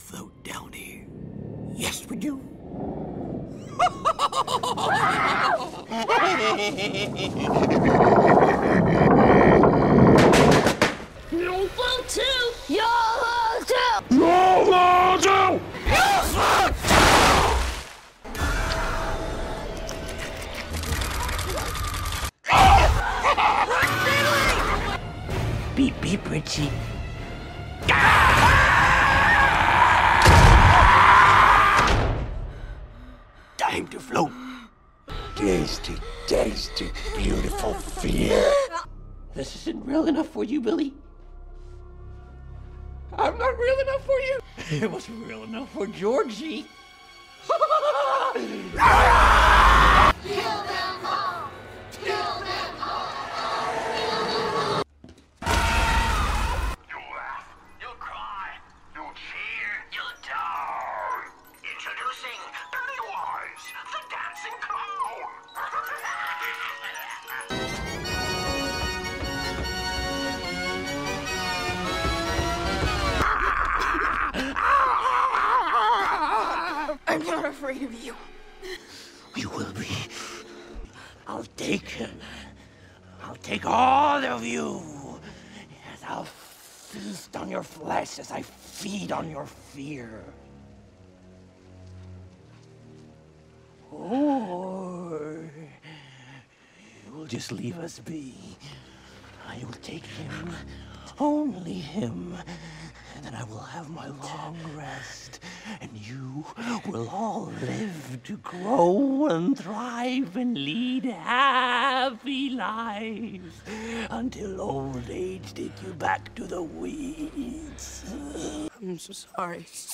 دانلود صدای خنده و حرف زدن پنی وایز از ساعد نیوز با لینک مستقیم و کیفیت بالا
جلوه های صوتی